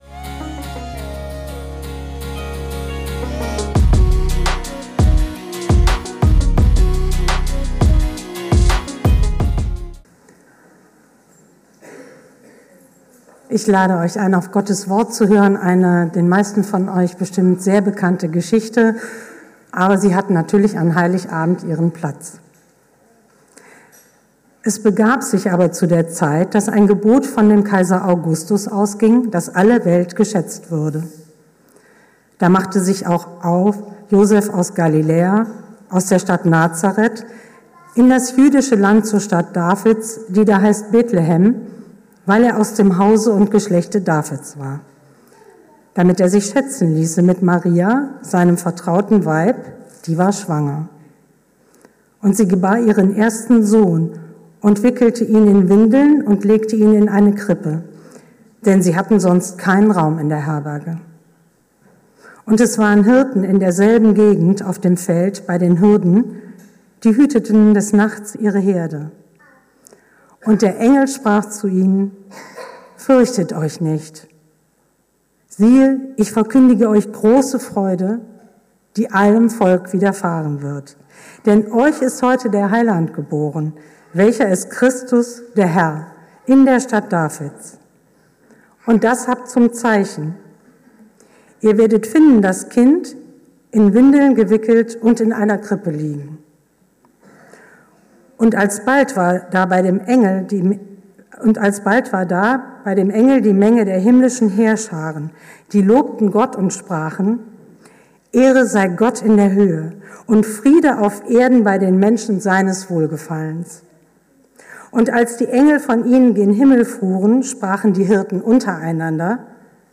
Predigt zur Christvesper 2024